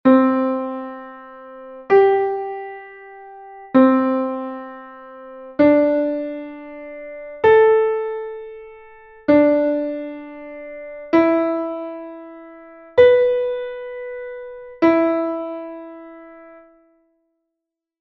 5th practice
practica_previa_intervalo_quinta.mp3